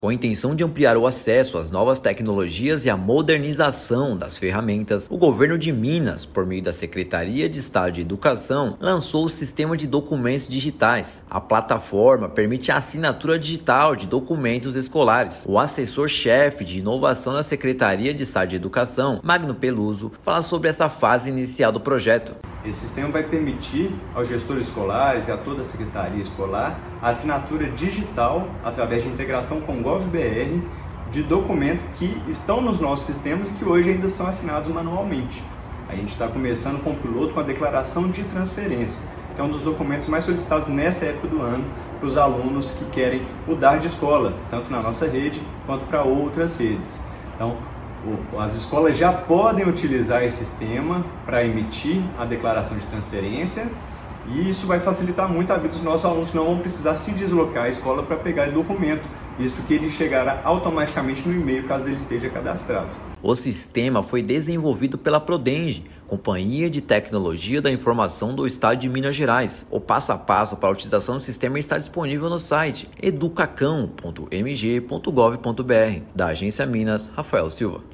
Novo sistema eDoc agiliza emissões e elimina necessidade de deslocamento presencial às escolas estaduais. Ouça matéria de rádio.